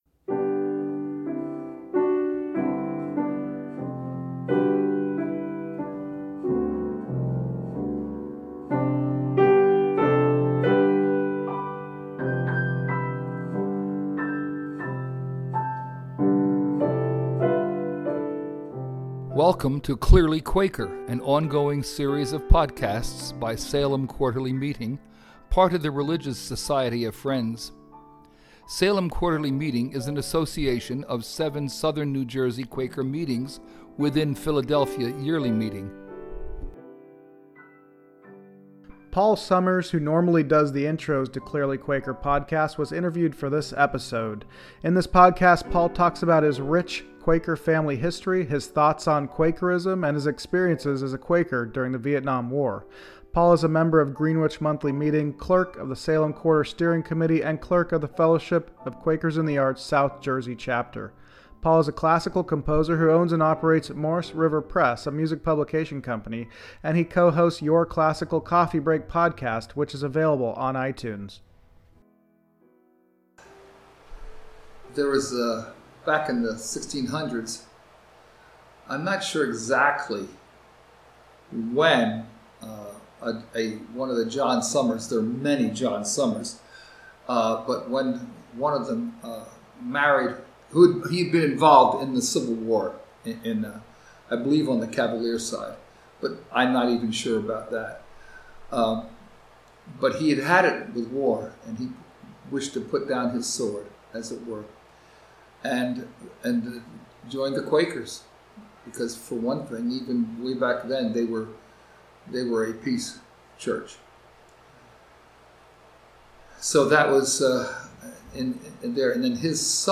was interviewed for this episode.
piano.